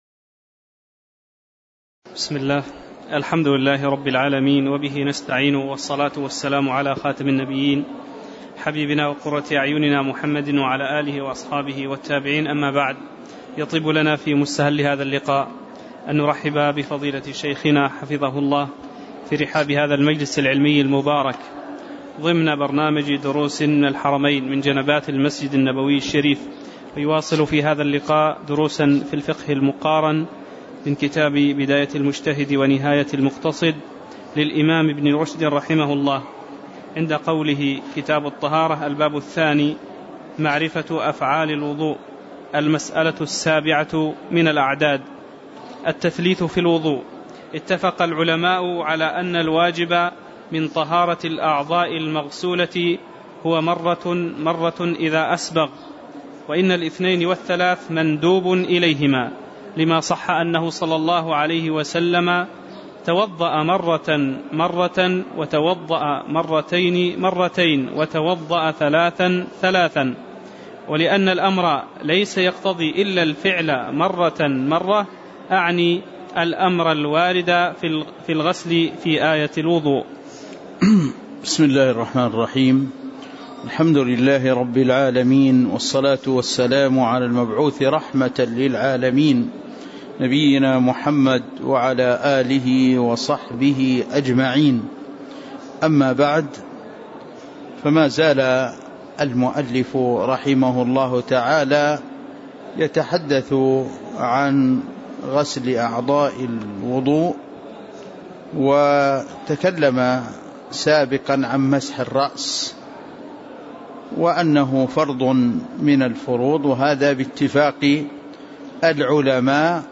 تاريخ النشر ٢٢ جمادى الآخرة ١٤٣٩ هـ المكان: المسجد النبوي الشيخ